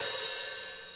[ACD] - JPlatinumPaidInFull Hat.wav